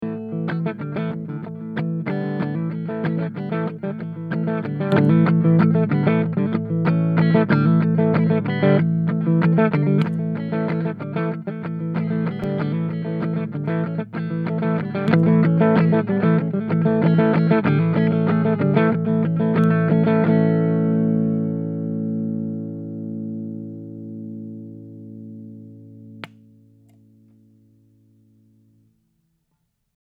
Here’s a clip that demonstrates only switching between normal and boost modes:
drx_boost.mp3